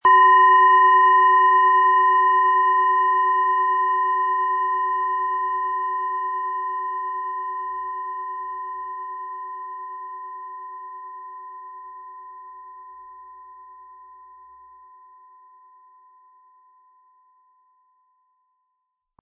PlanetentonBiorythmus Geist
SchalenformBihar
HerstellungIn Handarbeit getrieben
MaterialBronze